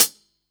Closed Hats
HIHAT775.WAV